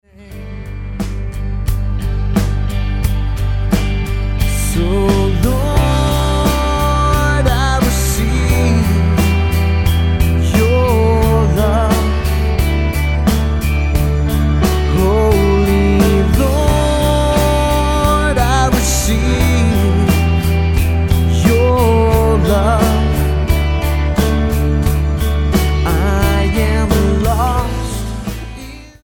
Style: Rock Approach: Praise & Worship